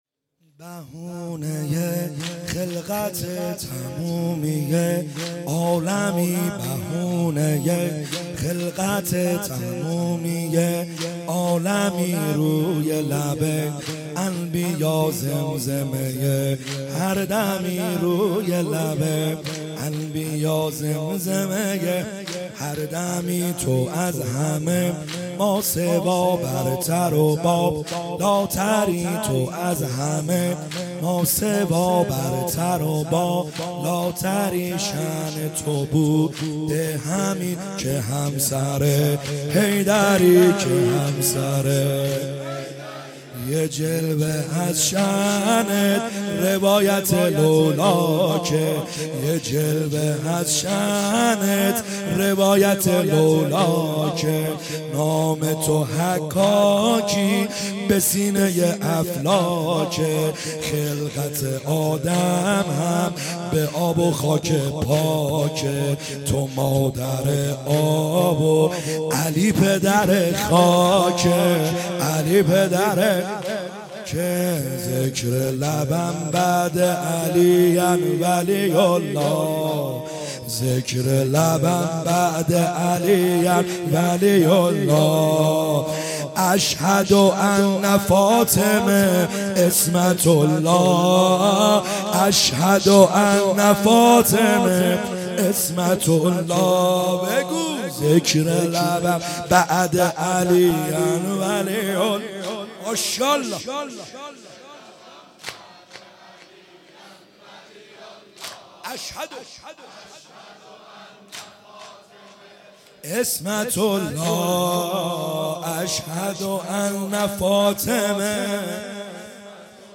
خیمه گاه - بیرق معظم محبین حضرت صاحب الزمان(عج) - واحد | بهونه خلقته تمومیه عالمی